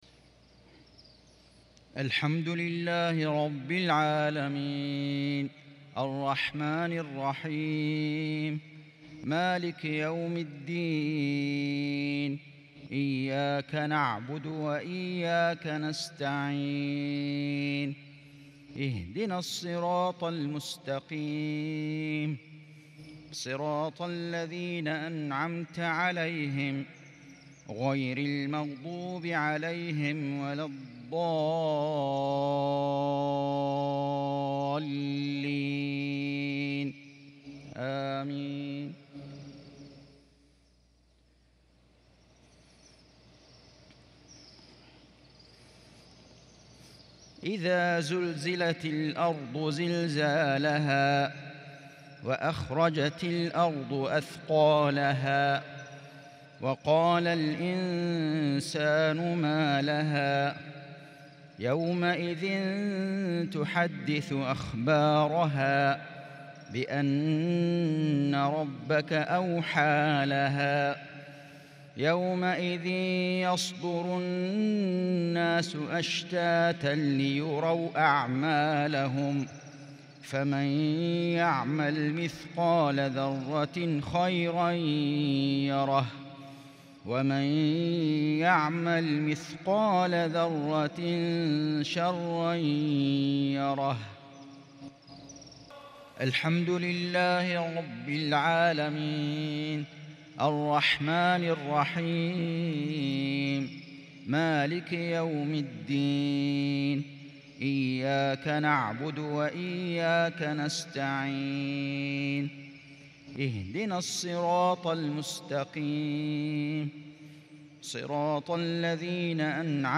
مغرب الإثنين 26 شوال 1442هـ سورتي الزلزلة والقارعة | maghrib prayer from surat zalzalah & Al-Qari'ah 7-6-2021 > 1442 🕋 > الفروض - تلاوات الحرمين